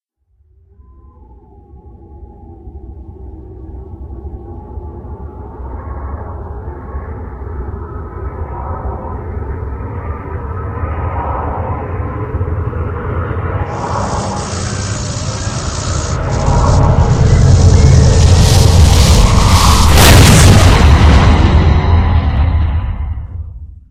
psi_storm_01.ogg